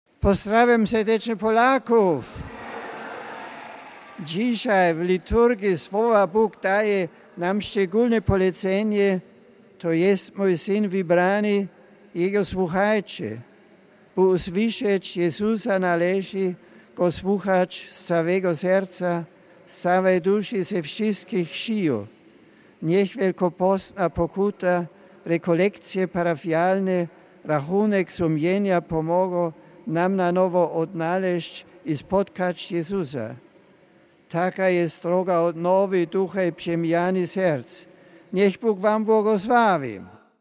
Pozdrawiając Polaków Benedykt XVI zachęcił do odnowy ducha poprzez podjęcie praktyk wielkopostnych.
Słuchaj Papieża po polsku: RealAudio